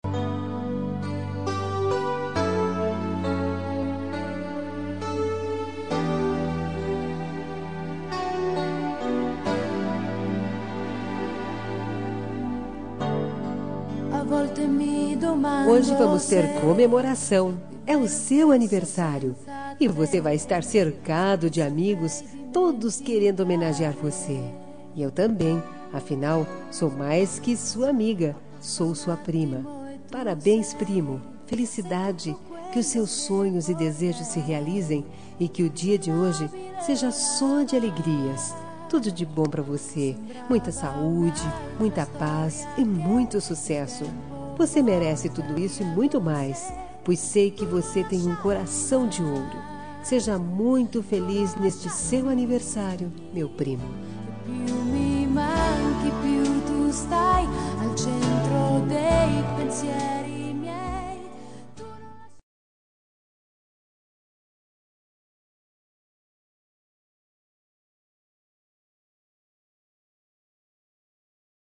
Aniversário de Primo – Voz Masculina – Cód: 042824